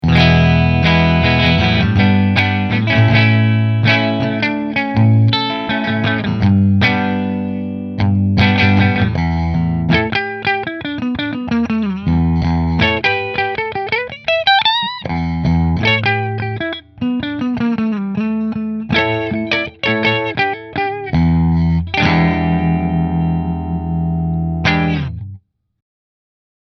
• 2 Wagnor Humbuckers
McNaught Custom Guitars Vintage Single Cut Dark Blue Middle Through Fender